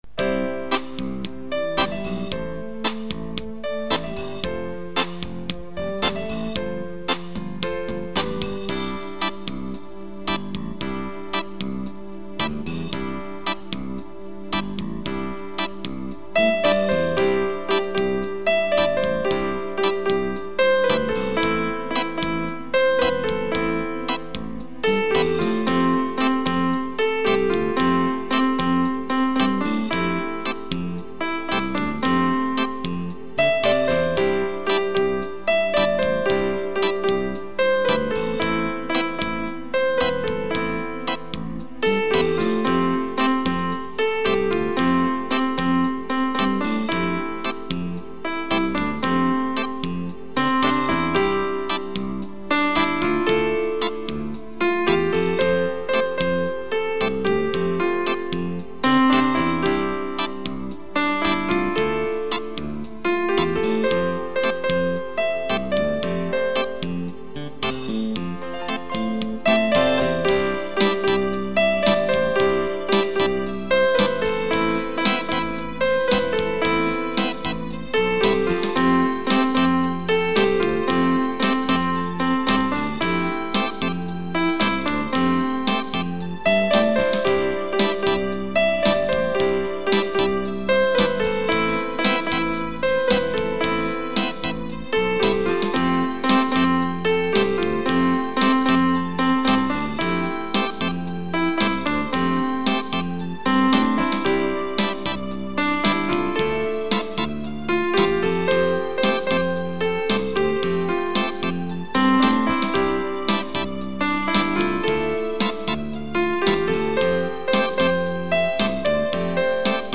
Романтическая музыка